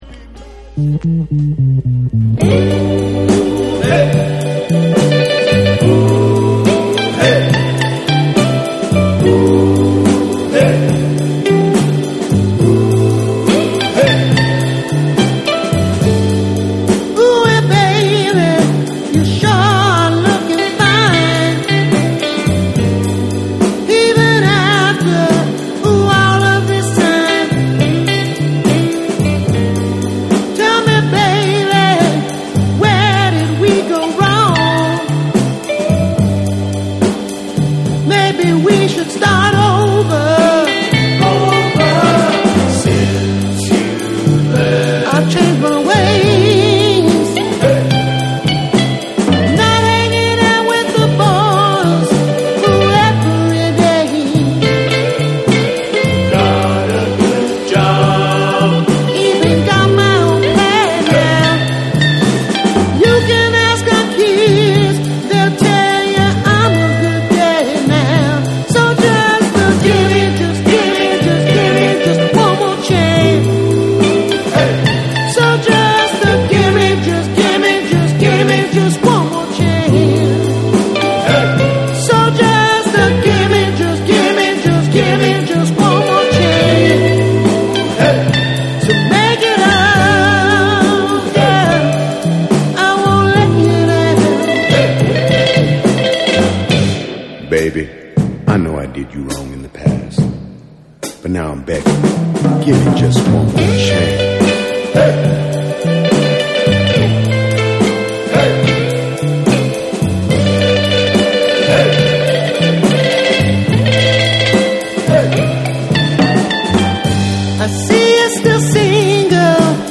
SOUL & FUNK & JAZZ & etc / MIX CD / NEW RELEASE(新譜)